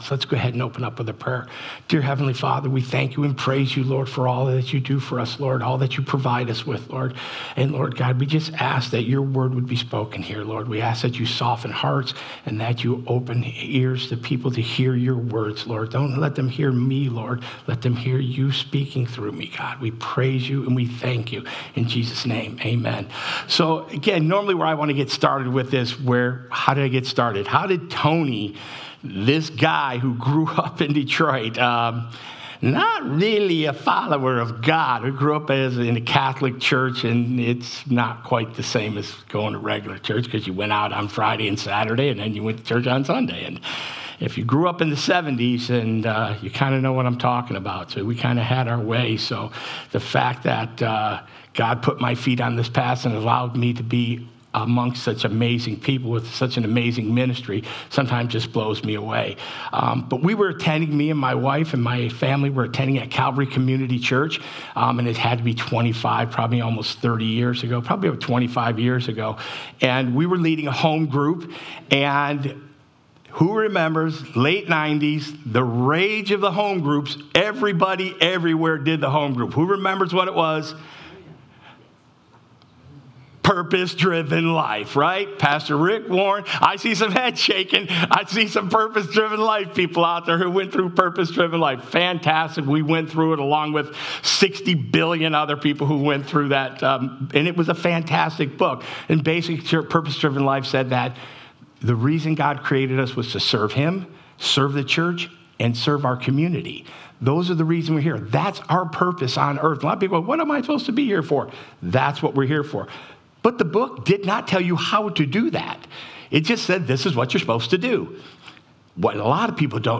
Guest Speakers Passage: Matthew 25:31-46 Service Type: Sunday Morning « Genesis-In the Beginning